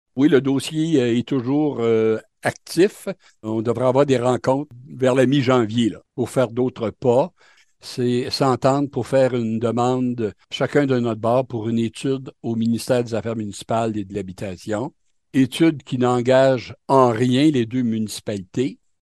Gaétan Guindon, maire de Denholm